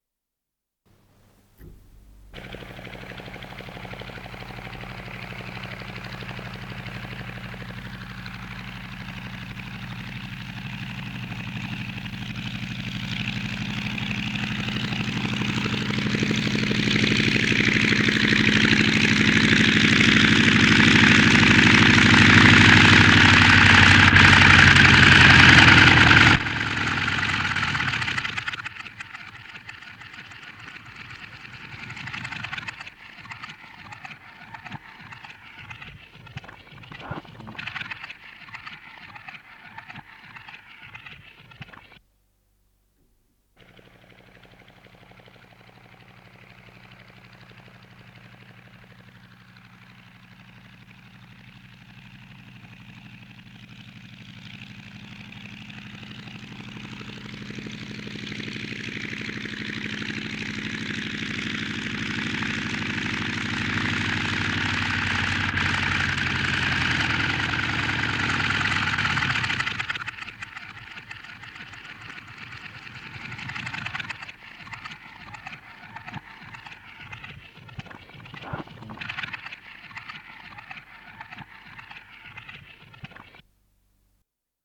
с профессиональной магнитной ленты
Название передачиПосадка самолёта
РедакцияШумовая
Скорость ленты38 см/с
ВариантМоно